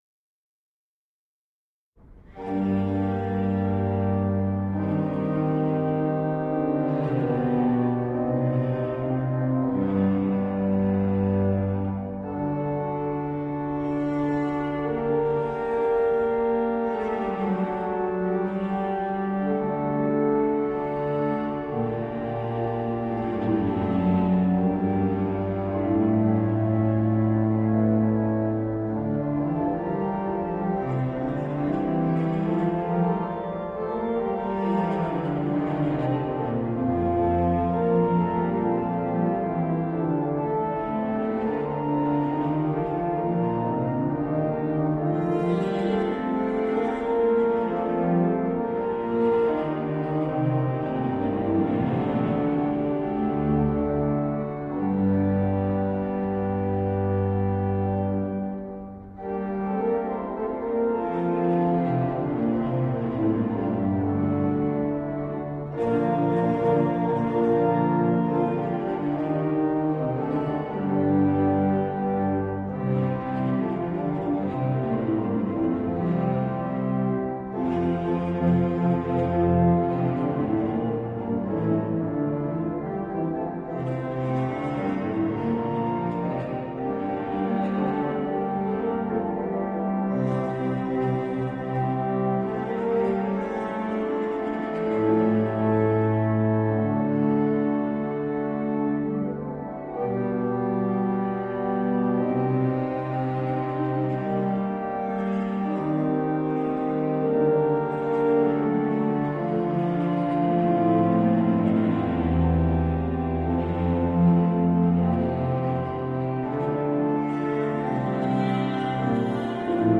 Classical, Baroque, Instrumental
Viola da Gamba